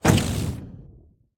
Minecraft Version Minecraft Version 25w18a Latest Release | Latest Snapshot 25w18a / assets / minecraft / sounds / entity / shulker / shoot3.ogg Compare With Compare With Latest Release | Latest Snapshot
shoot3.ogg